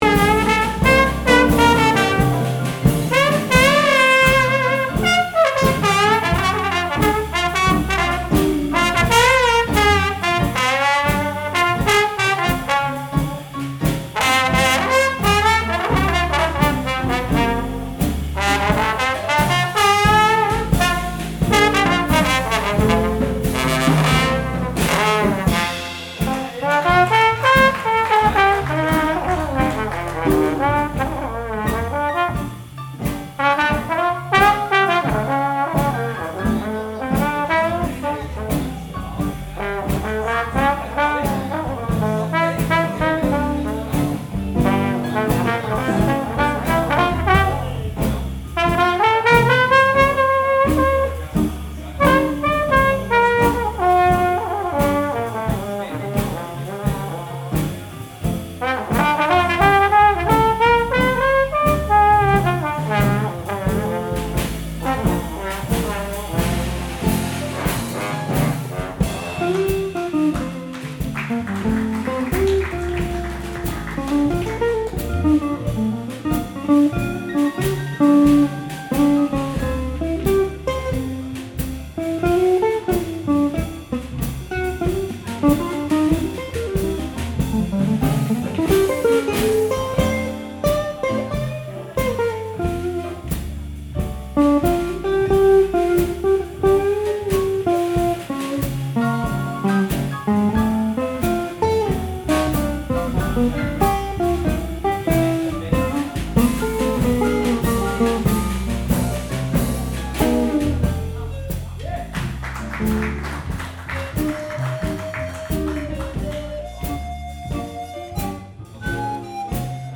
Jazz-Rubigen.mp3